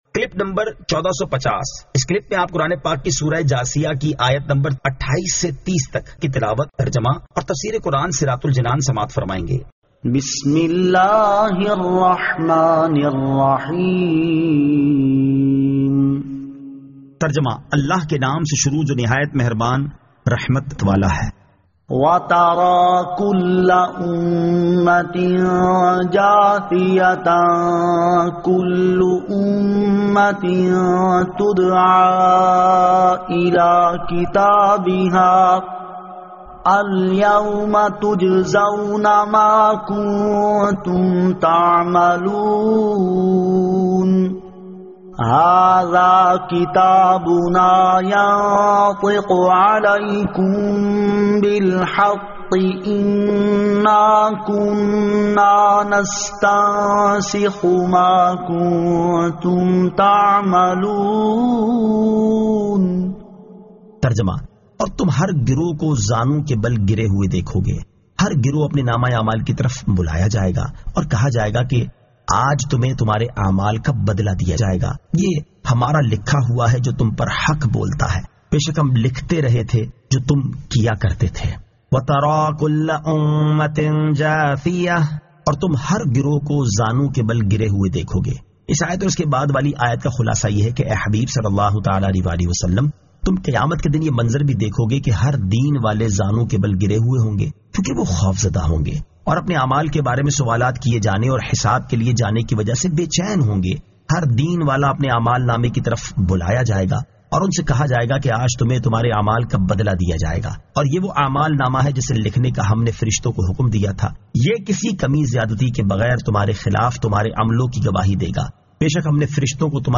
Surah Al-Jathiyah 28 To 30 Tilawat , Tarjama , Tafseer